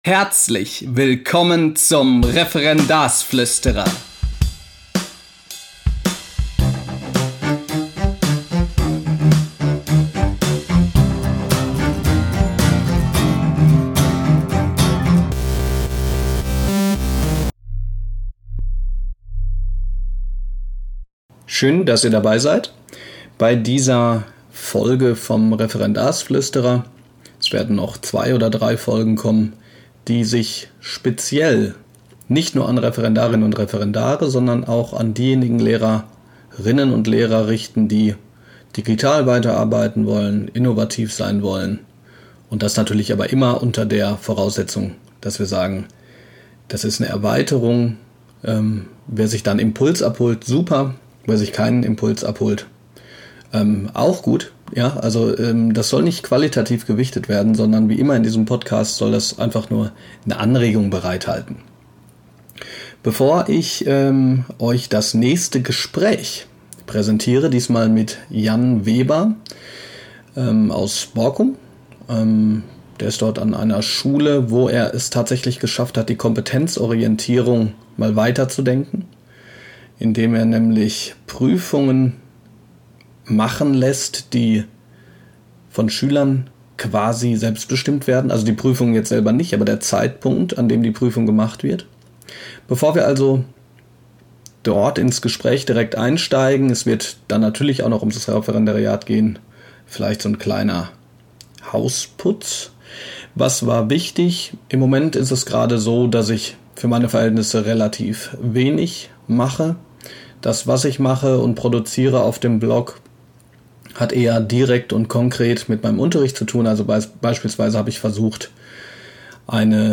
Diese Folge ist das zweite einiger Gespräche mit interessanten Persönlichkeiten, die ich auf dem Deutschen Lehrerforum geführt habe.